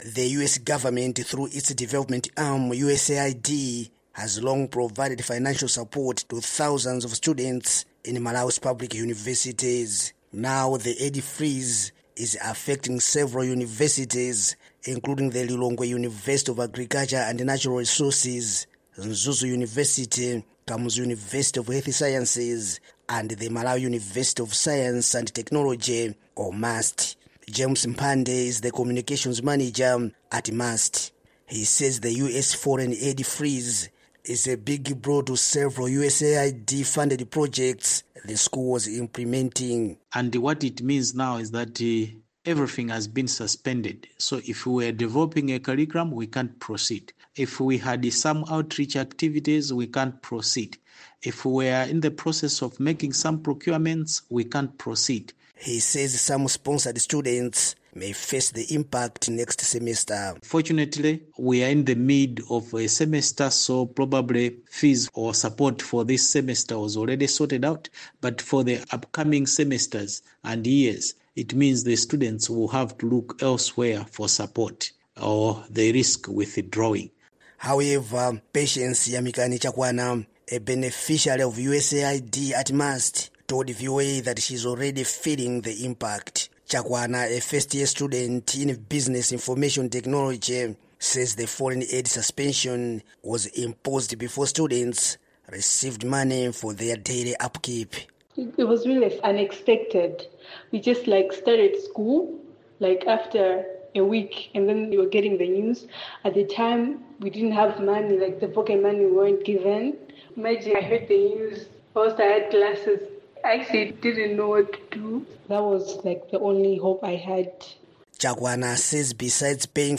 reports from Blantyre.